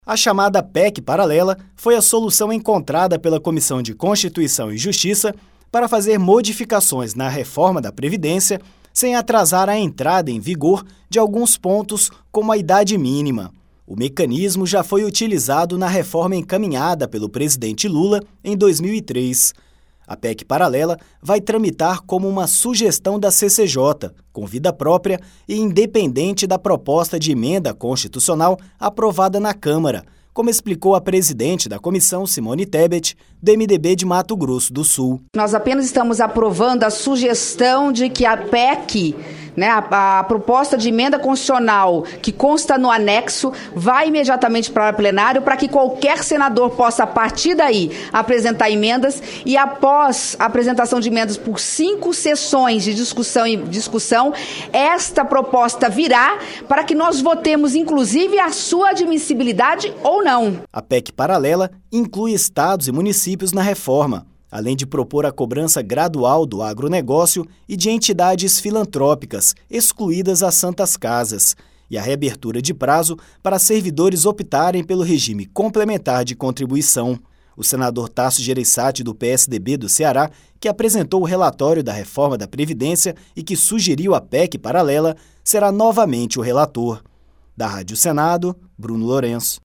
A Comissão de Constituição e Justiça (CCJ) aprovou o encaminhamento de uma proposta para correr em paralelo com a Reforma da Previdência. A presidente da CCJ, Simone Tebet (MDB-MS), explicou que, a partir da apresentação da proposta no plenário, os senadores terão cinco sessões para apresentarem emendas. A reportagem